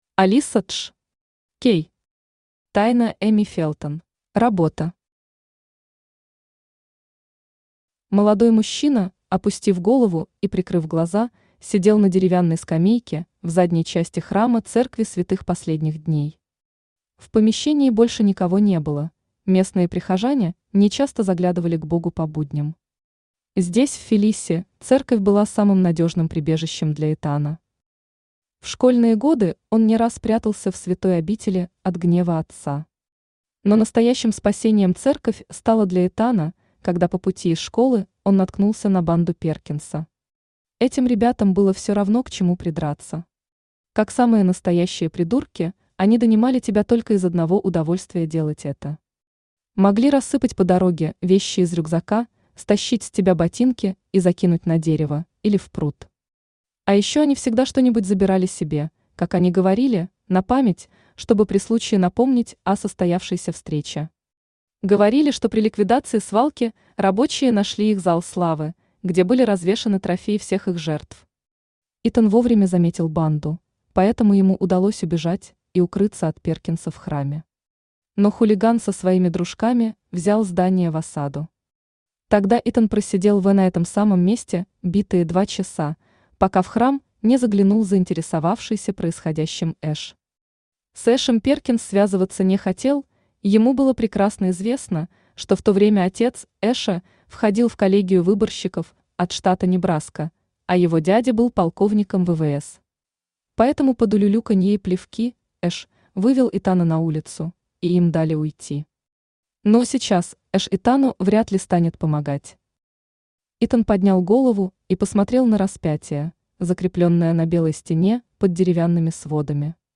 Аудиокнига Тайна Эми Фелтон | Библиотека аудиокниг
Кей Читает аудиокнигу Авточтец ЛитРес.